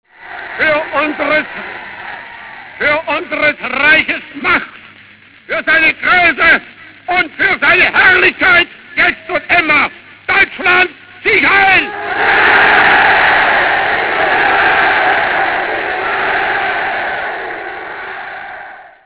discorso (file wav)